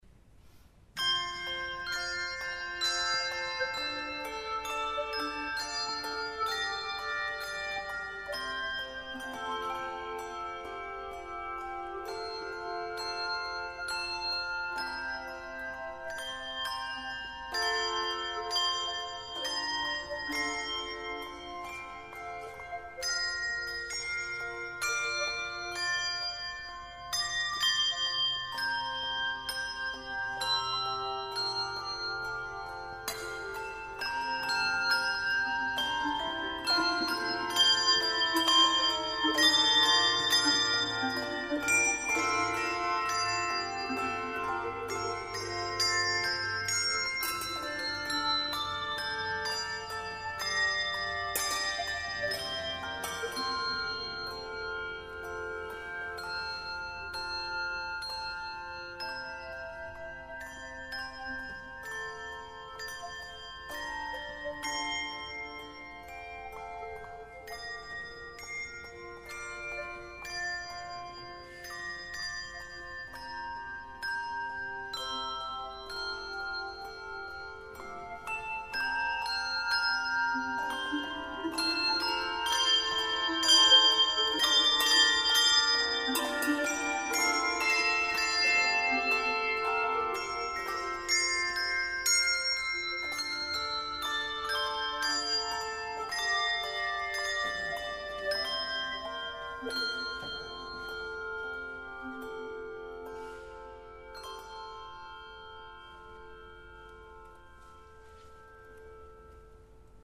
Handglocken
Die Musik der Handglocken ist durch ihren hoch resonanten und einzigartig anziehenden Klang charakterisiert, der durch die Obertöne zustande kommt.
Erfreuen Sie sich an der wunderbaren Musik und dem zarten Glockenklang des 8-Hände (4-Spieler/innen) bestehenden Handglockenensembles "Mai", das mit großer Handfertigkeit 37 Glocken zum Erklingen bringt.